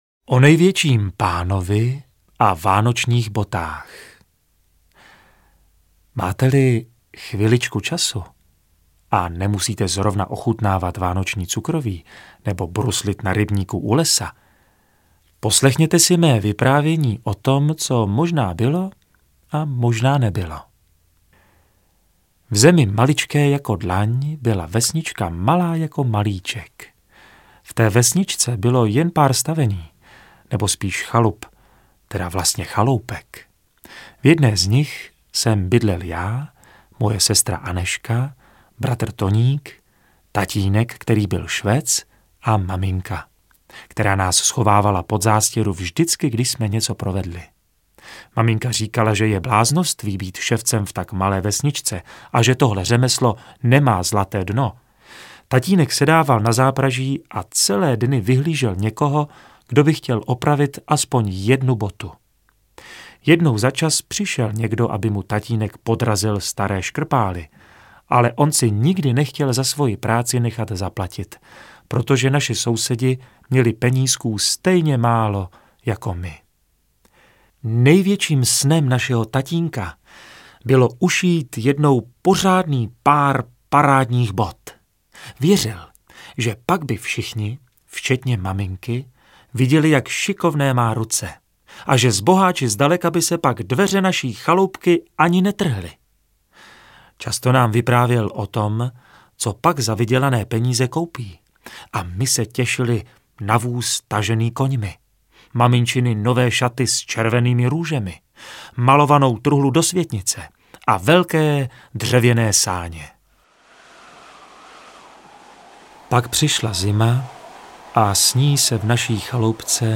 Interpret:  Otakar Brousek
AudioKniha ke stažení, 24 x mp3, délka 2 hod. 34 min., velikost 139,4 MB, česky